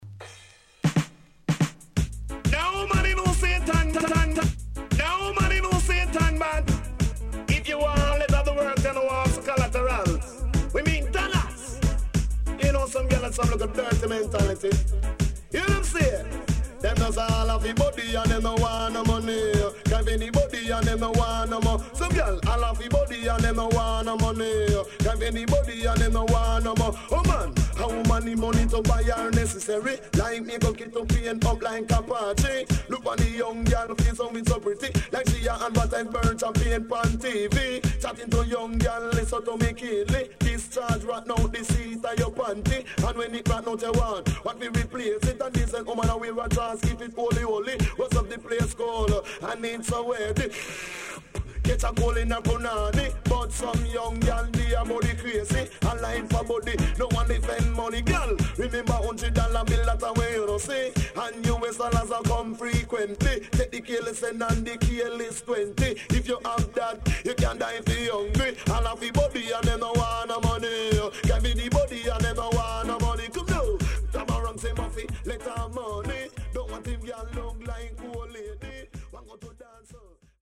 HOME > Back Order [DANCEHALL LP]